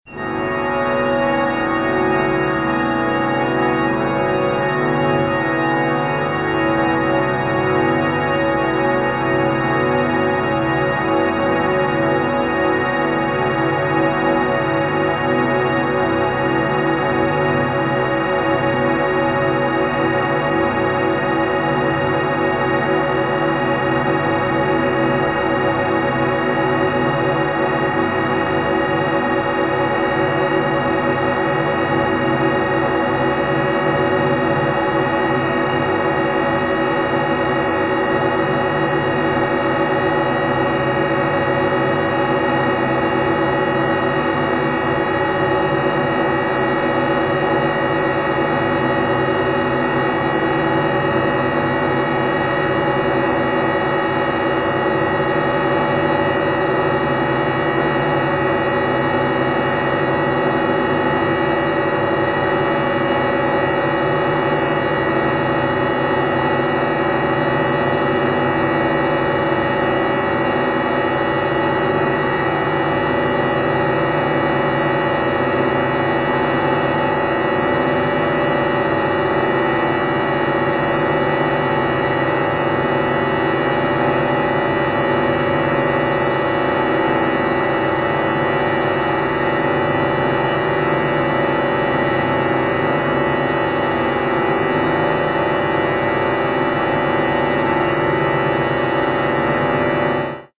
24 Instrumente und Elektronik
sketch of the first 100 seconds (shift of 100c):